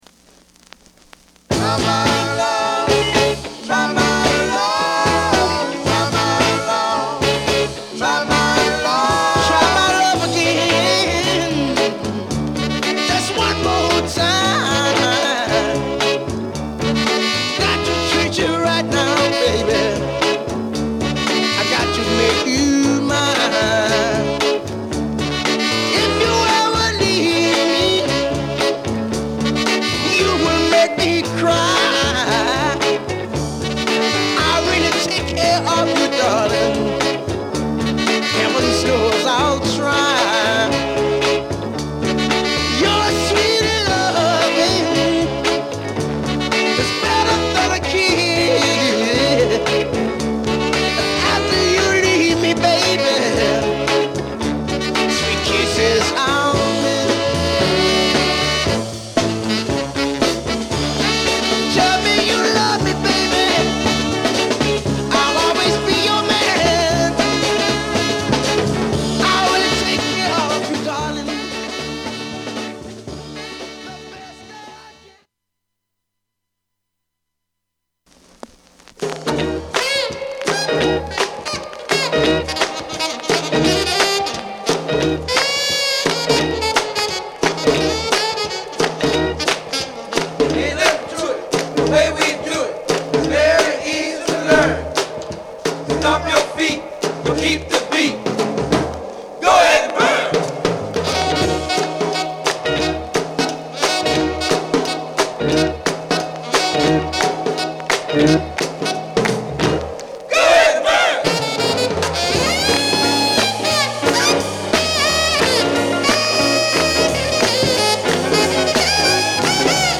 R&BHOTTEST SOUL BALLAD & R&B !!画像クリックで試聴出来ます。